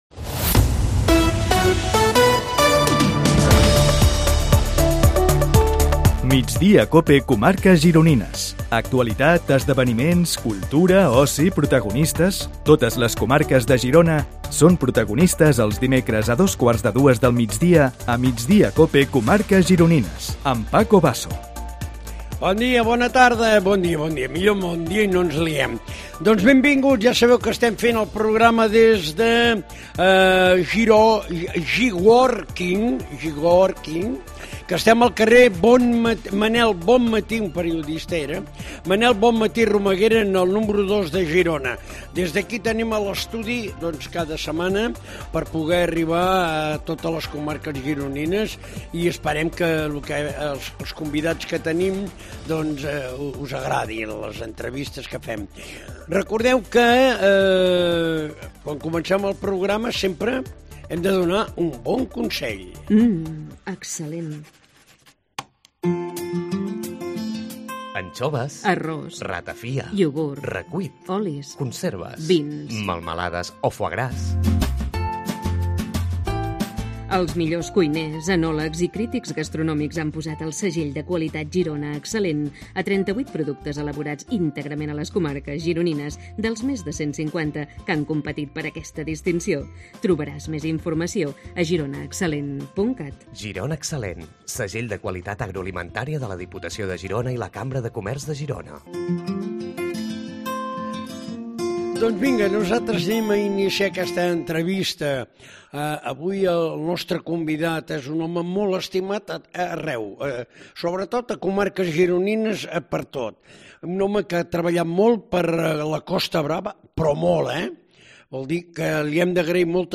AUDIO: Entrevistem els protagonistes de l'actualitat a les comarques gironines
El nostre és un programa de ràdio que compte amb els millors ingredients.